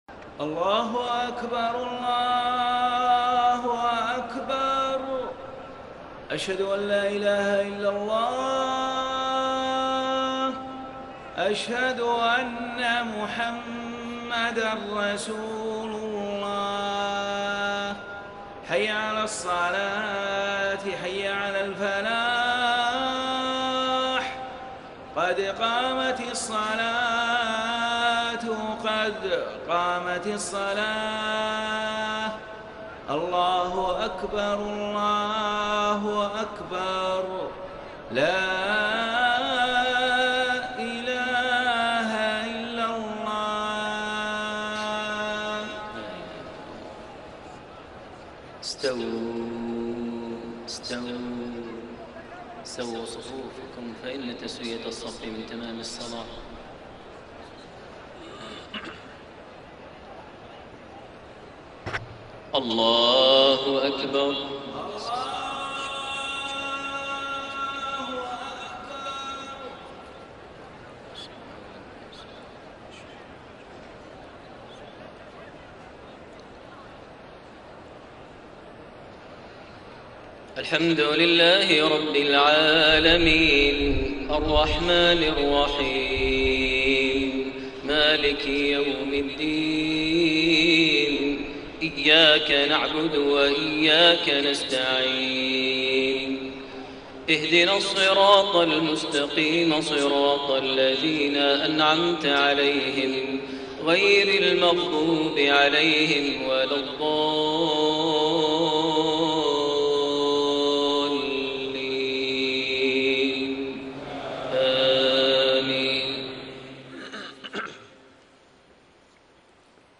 صلاة الفجر 27 رجب 1432هـ | فواتح سورة محمد 1-19 > 1432 هـ > الفروض - تلاوات ماهر المعيقلي